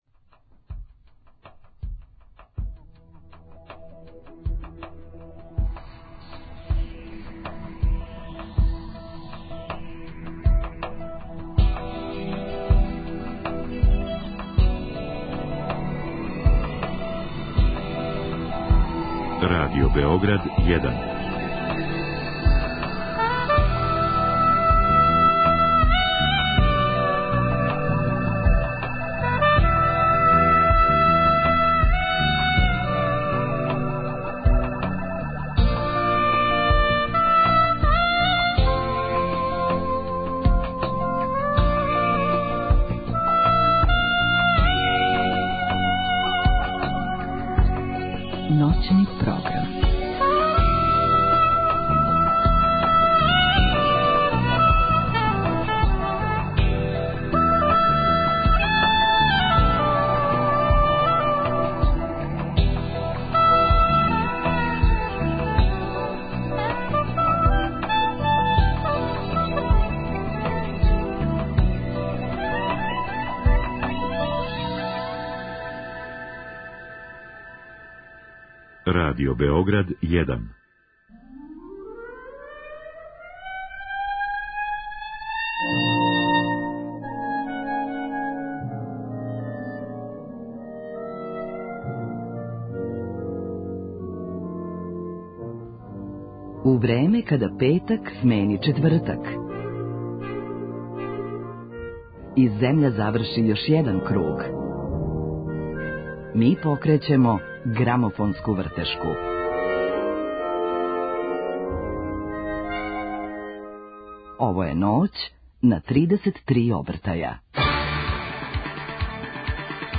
У последњој новембарској Ноћи на 33 обртаја нећемо бити у брзини која је у називу наше емисије, него у темпу рока.